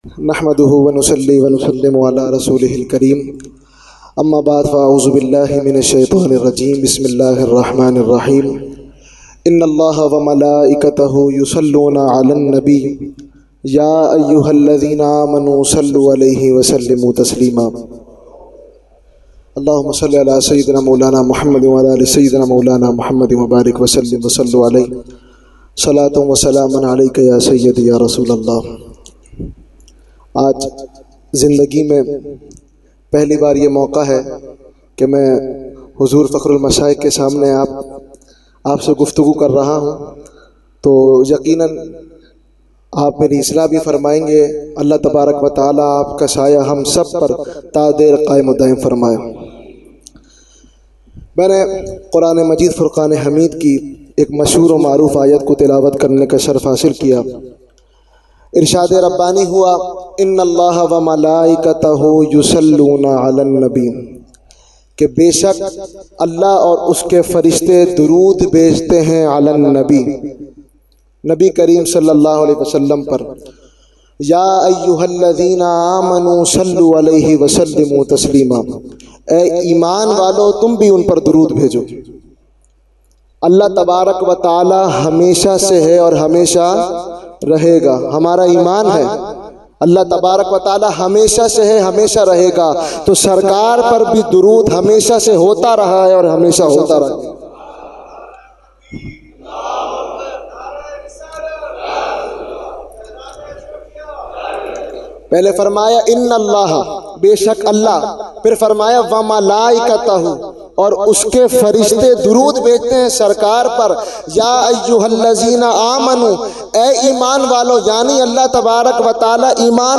Jashne Subhe Baharan held 29 October 2020 at Dargah Alia Ashrafia Ashrafabad Firdous Colony Gulbahar Karachi.
Category : Speech | Language : UrduEvent : Jashne Subah Baharan 2020